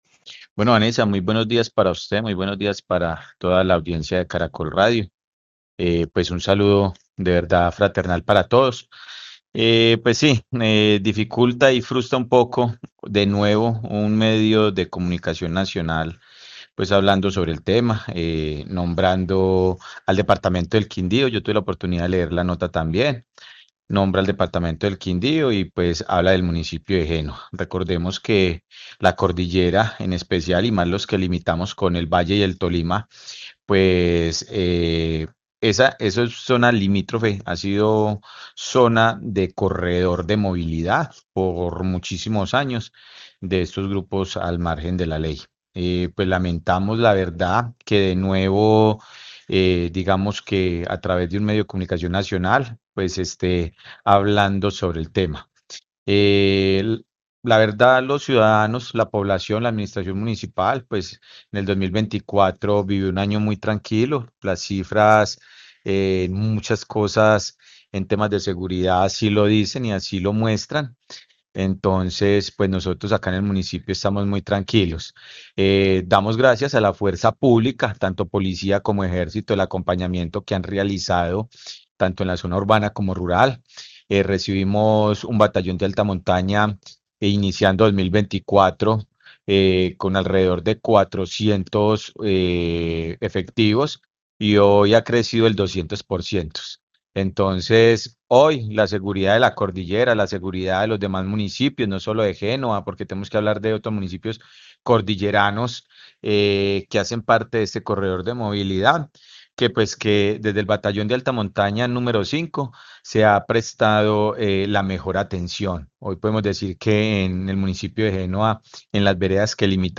Alcalde de Génova